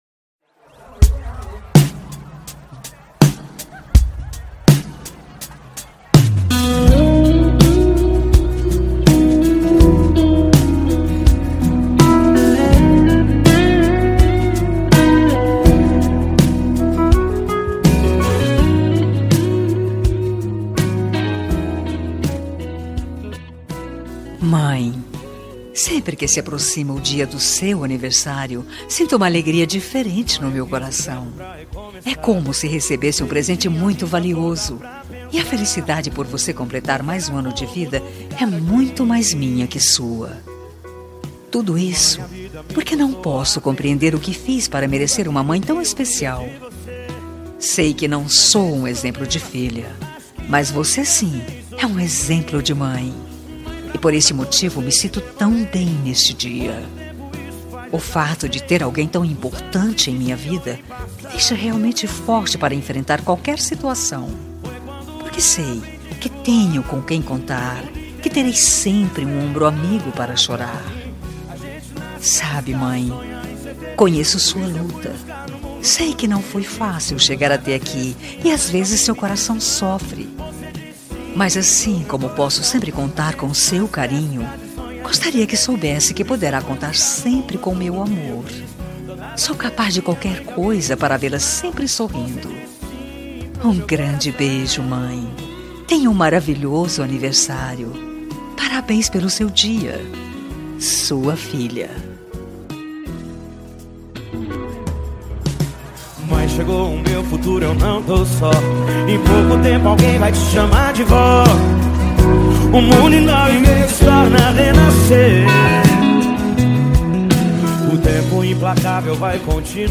Aniversário de Mãe – Voz Feminina – Cód: 035381